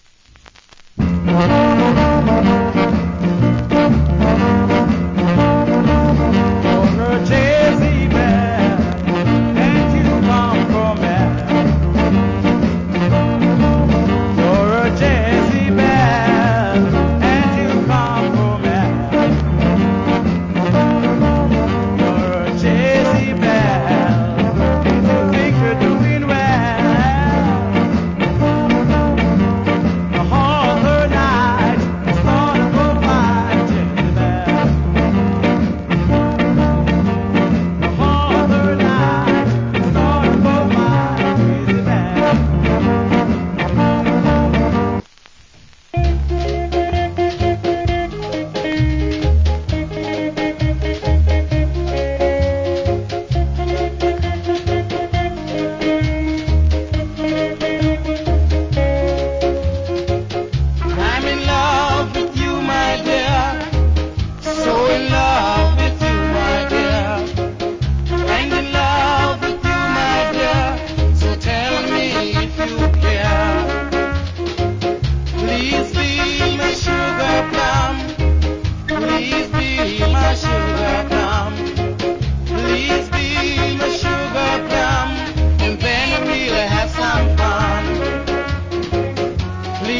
Nice Jamaican R&B Vocal.